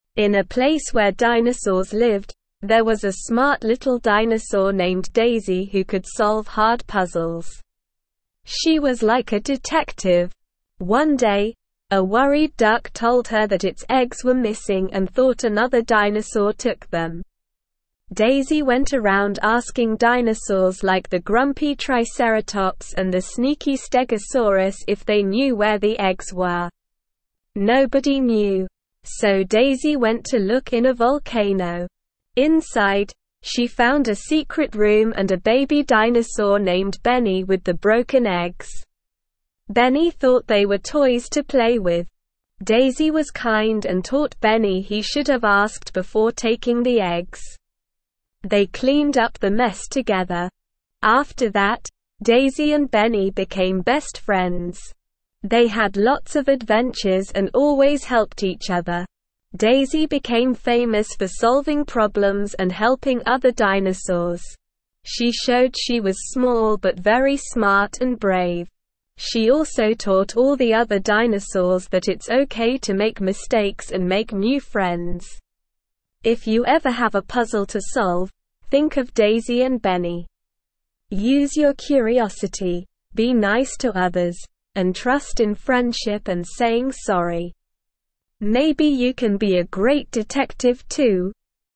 Slow
ESL-Short-Stories-for-Kids-Lower-Intermediate-SLOW-Reading-The-Dinosaur-Detective.mp3